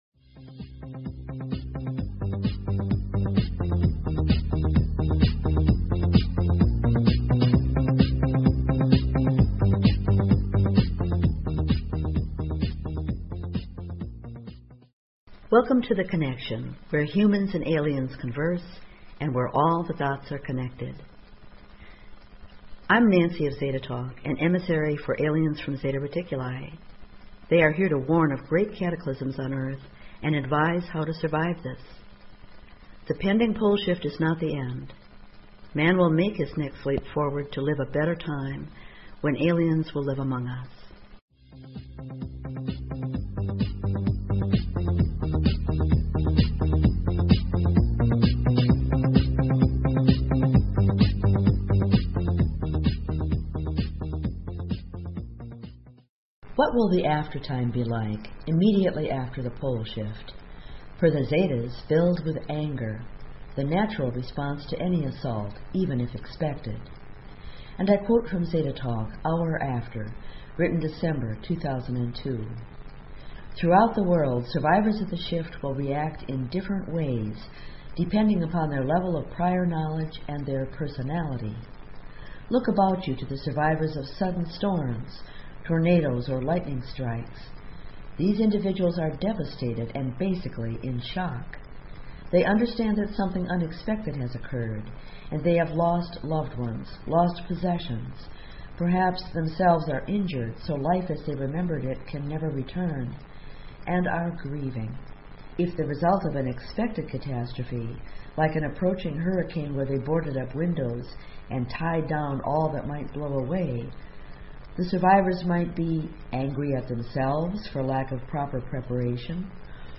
Talk Show Episode, Audio Podcast, The_Connection and Courtesy of BBS Radio on , show guests , about , categorized as